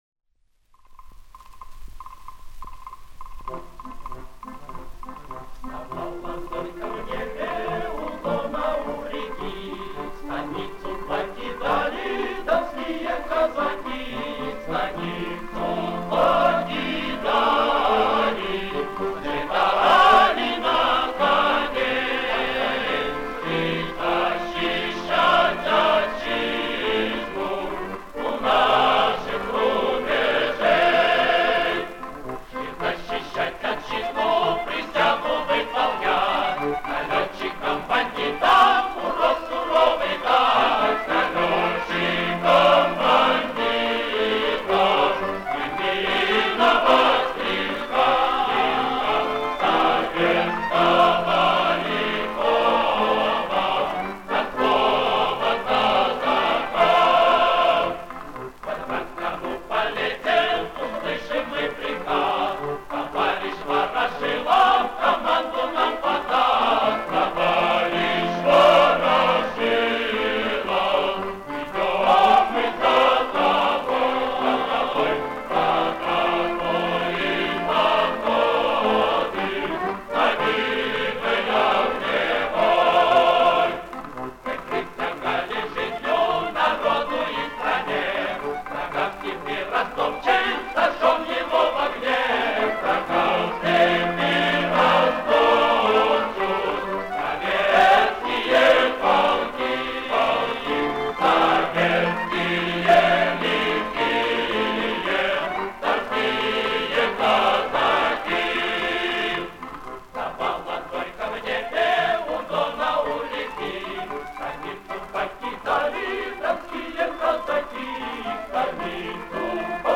Ещё одно повышение качества